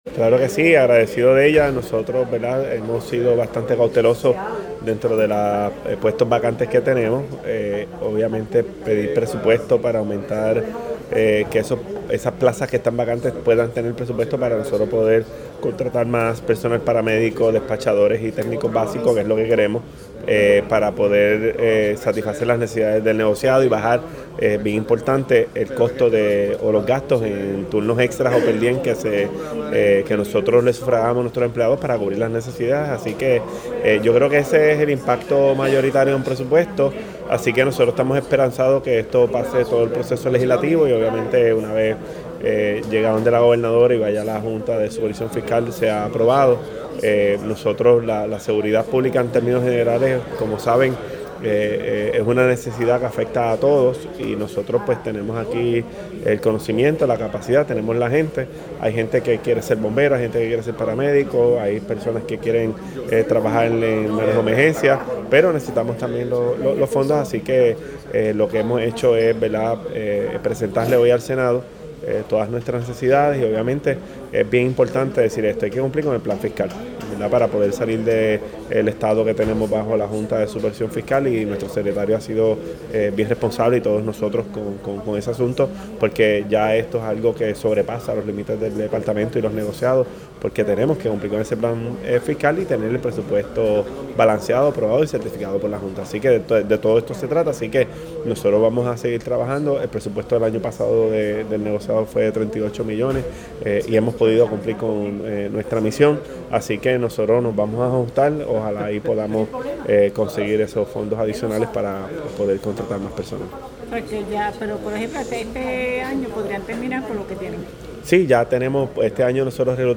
“Hicimos una petición para 100 plazas en el presupuesto recomendado”, indicó el titular de Emergencias Médicas.